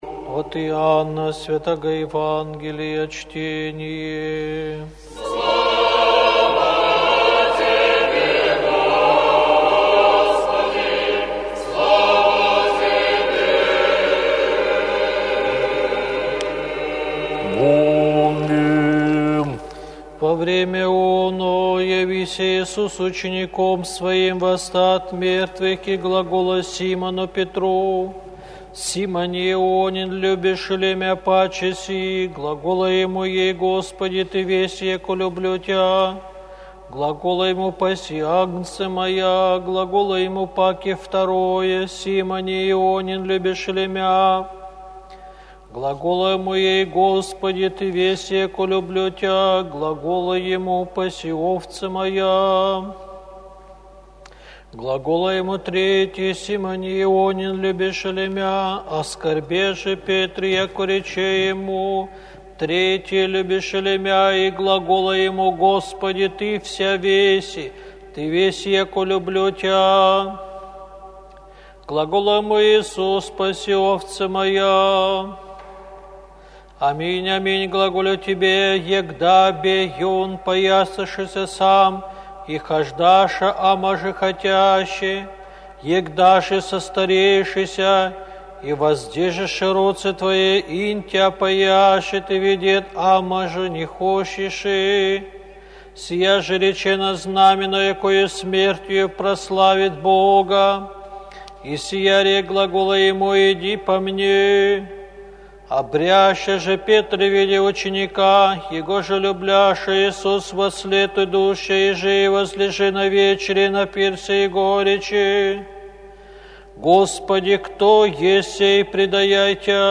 ЕВАНГЕЛЬСКОЕ ЧТЕНИЕ НА УТРЕНЕ